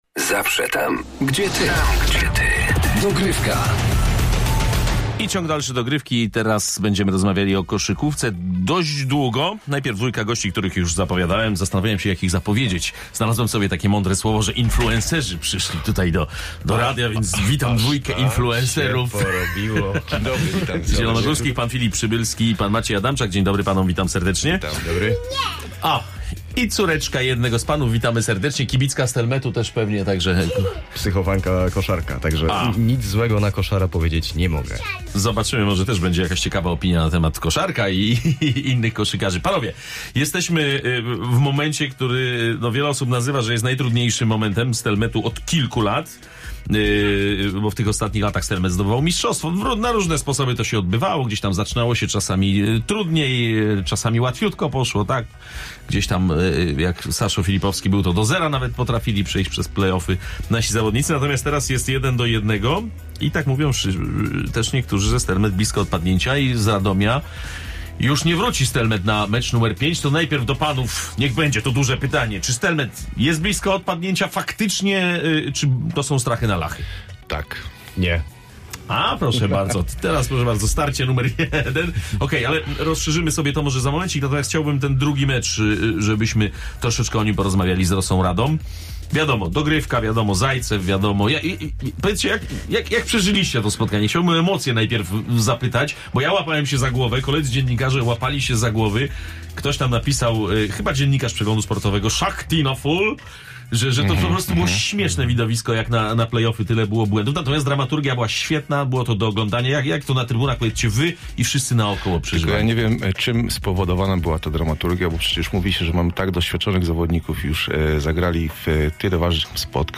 Zaprosiliśmy dziś do studia